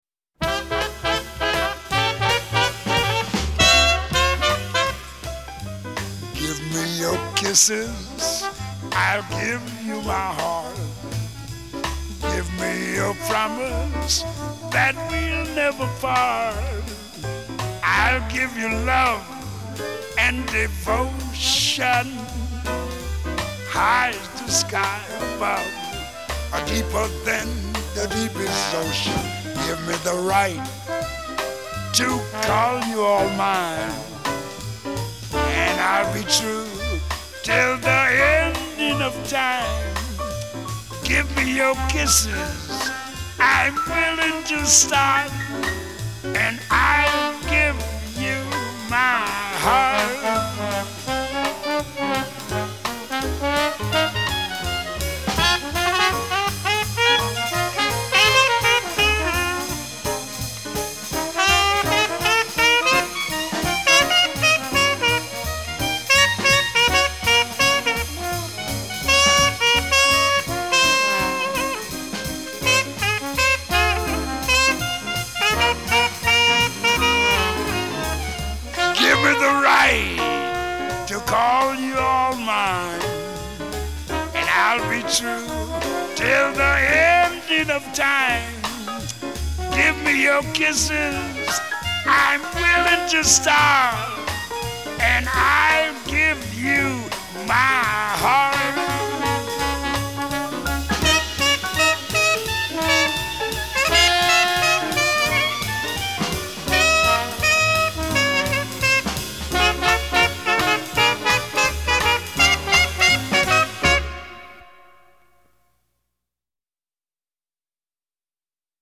他即兴的演奏和歌唱能像月光一样轻盈。